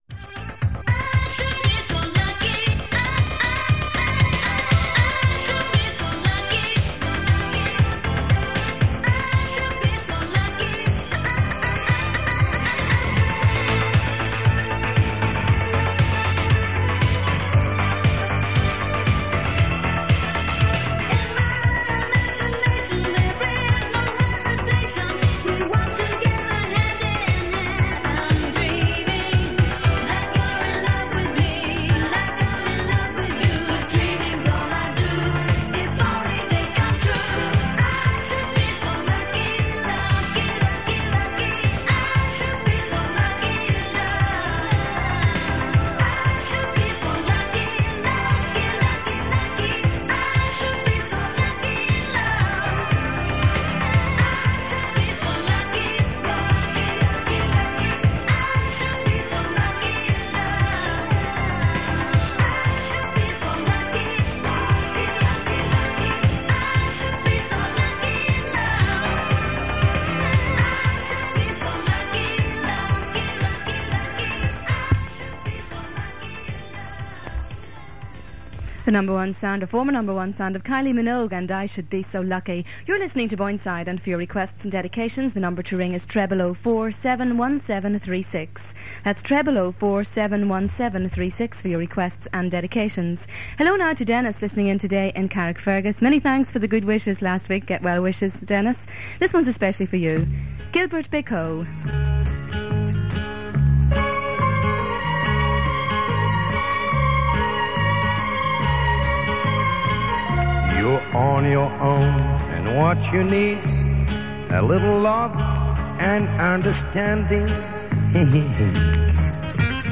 This recording of Boyneside Radio North was made on Easter Sunday 1988 and features an unidentified DJ with a mixture of pop and oldies. Requests are mostly from the Northern counties of Down and Armagh. Adverts feature businesses around the Newry area and an advertising promo contains Northern phone numbers.